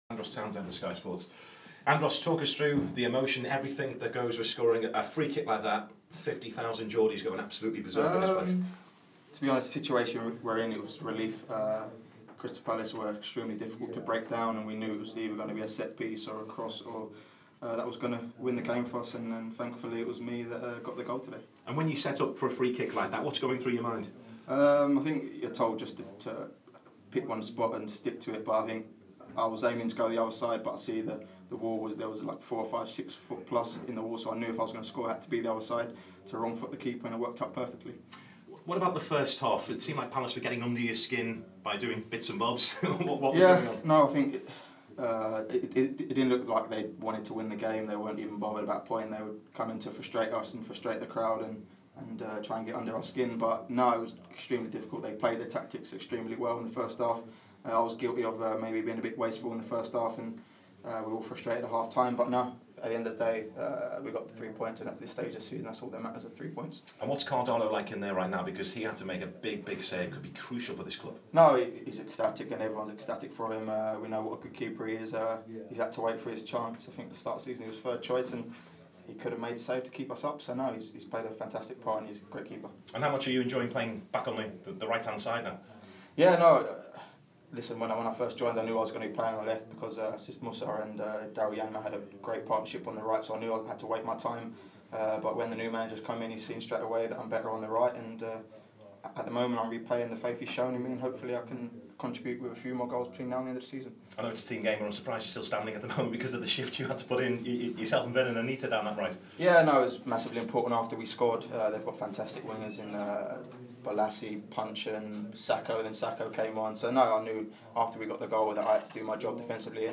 Andros Townsend talks to Sky Sports following after his free-kick gave Newcastle all three points against Crystal Palace.